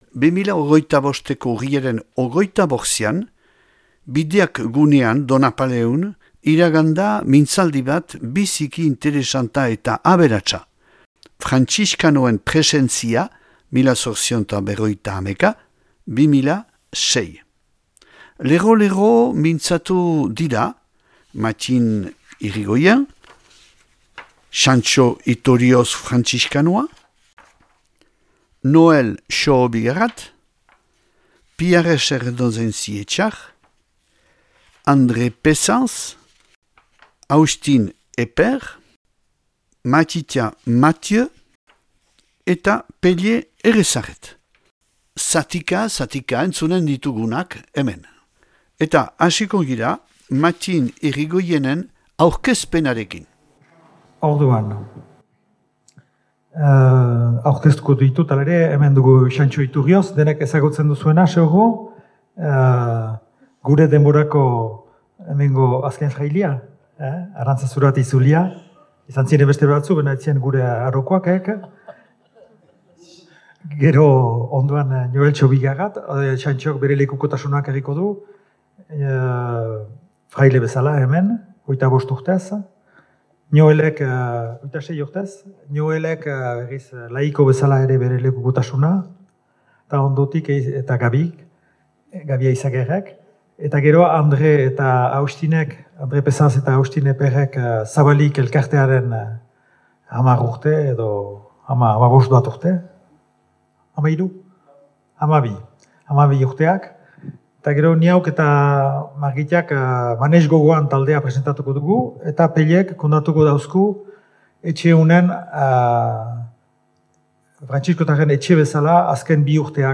2025ko Urriaren 25ean, Bideak gunean Donapaleun, iragan da mintzaldi bat biziki interesanta eta aberatsa : Frantziskanoen presentzia 1851-2006.